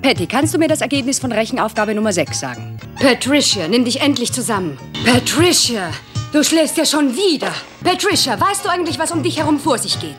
- Wieder brach Schulz mit einer Tradition und gab P.Pattys Lehrerin eine menschliche Stimme.
cb-b3-skate-teacher.mp3